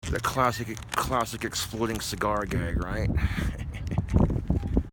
classic exploding cigar gag